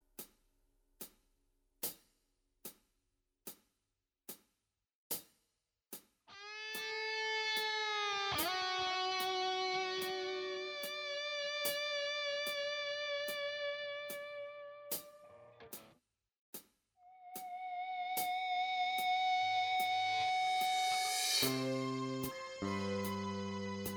Minus Acoustics Rock 4:58 Buy £1.50